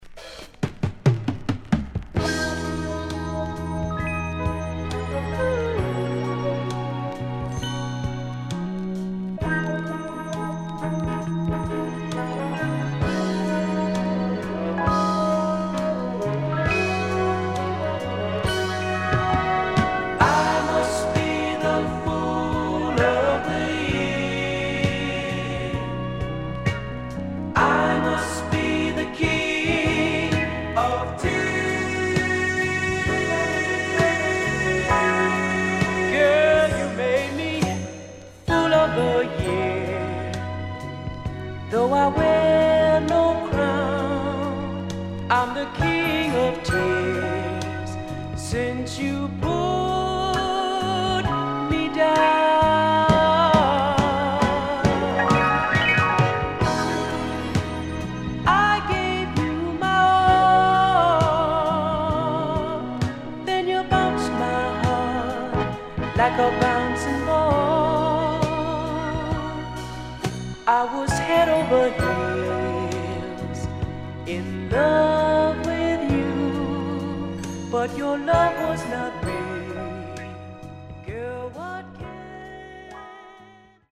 HOME > SOUL / OTHERS
SIDE A:少しチリノイズ入りますが良好です。